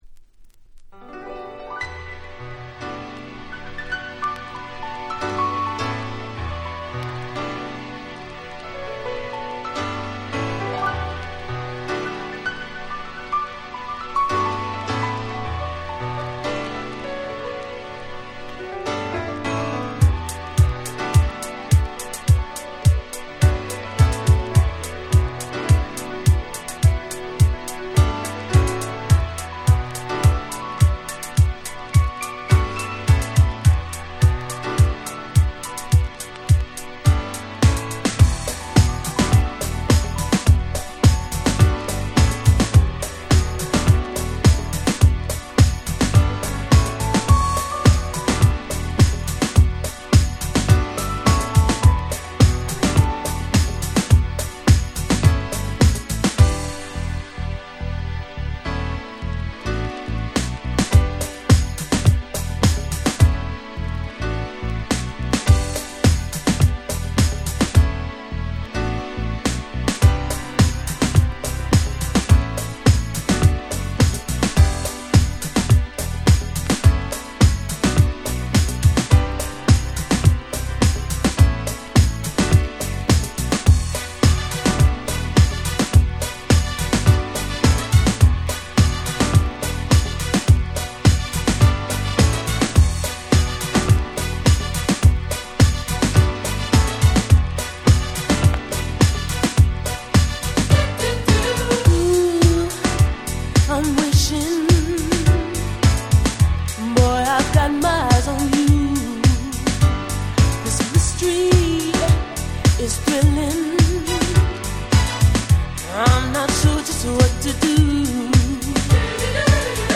89' Nice Cover R&B !!
Ground Beat調のLowなHouse Beatでめちゃくちゃ気持ちが良いです！！
歌もバッチリ！